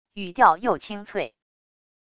语调又清脆wav音频